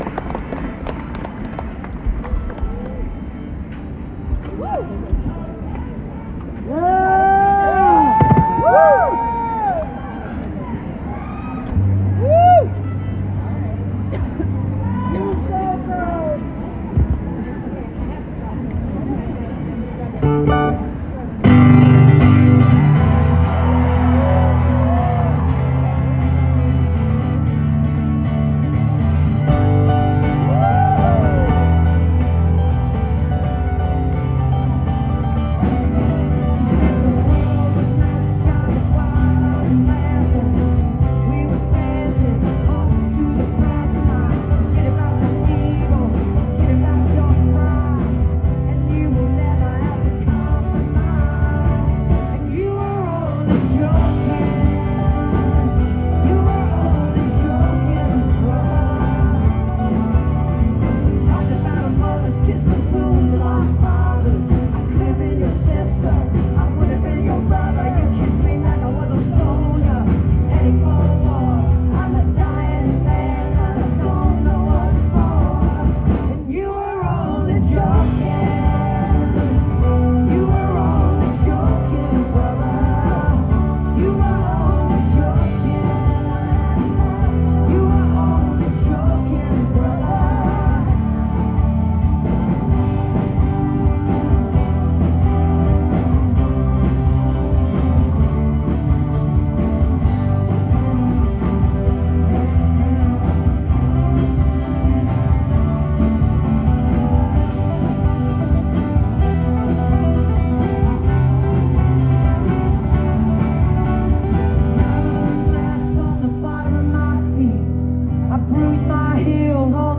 (band show)